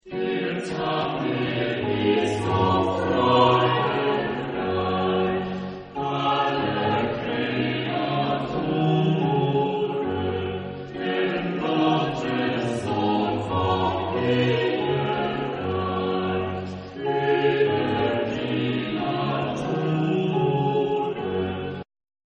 SATB (4 voix mixtes) ; Partition complète.
Choral.